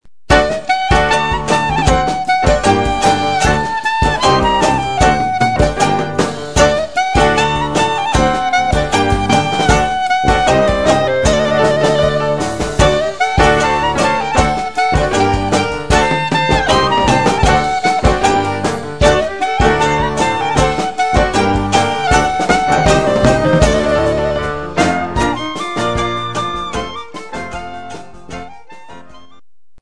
One of the best-known klezmer tunes